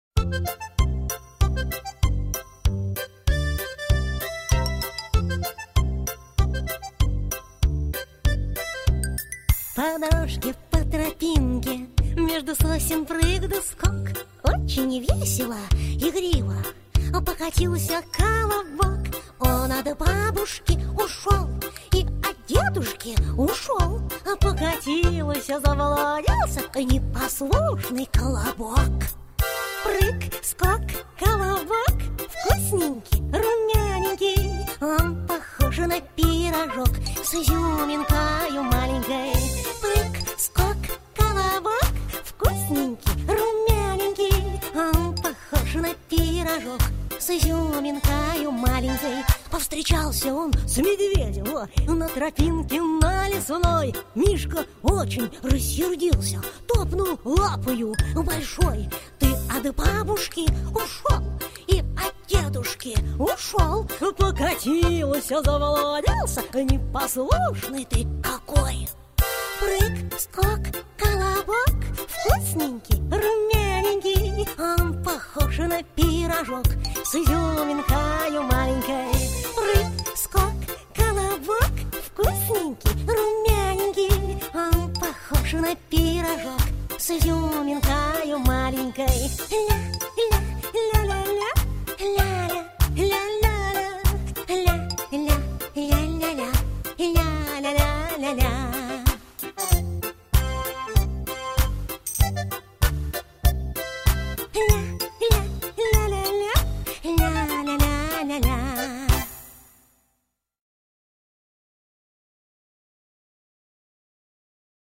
Детская веселая песенка.